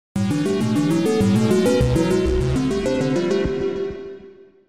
効果音のフリー素材です。
効果音4